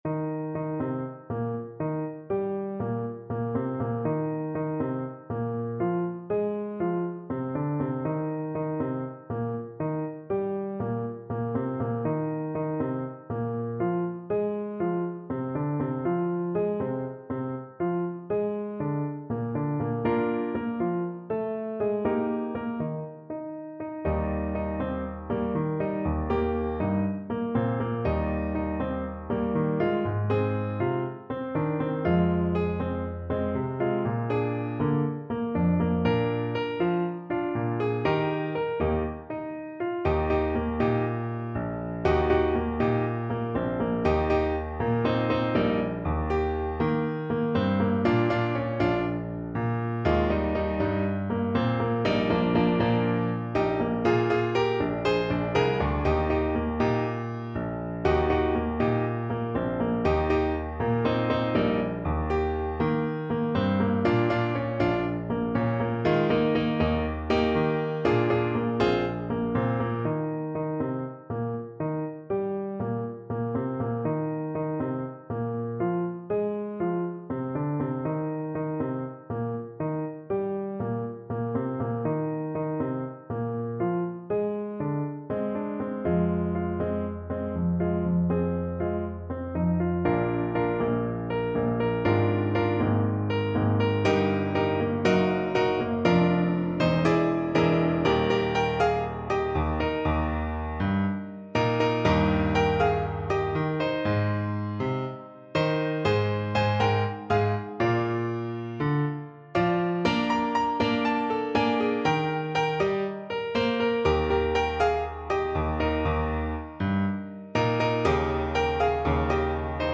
Alto Saxophone version
Alto Saxophone
4/4 (View more 4/4 Music)
Molto Allegro =160 (View more music marked Allegro)
Christmas (View more Christmas Saxophone Music)